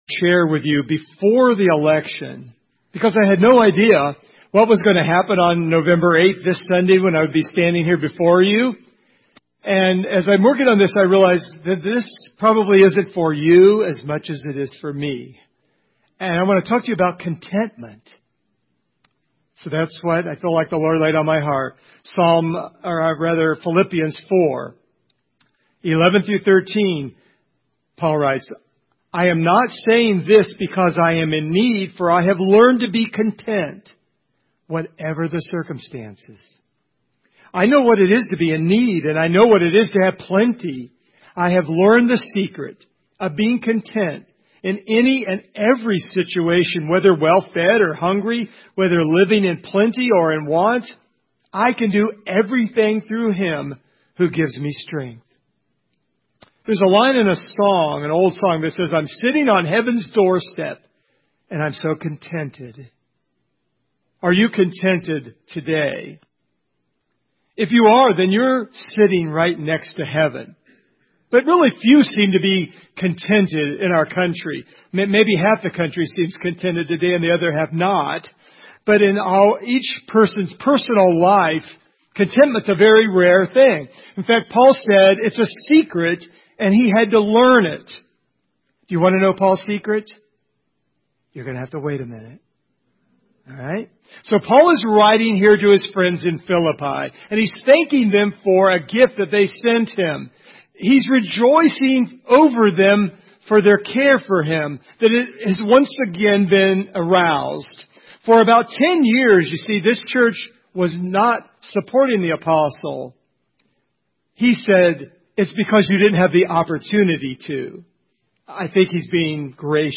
This sermon describes what content is not, is, and how Paul learned to be content.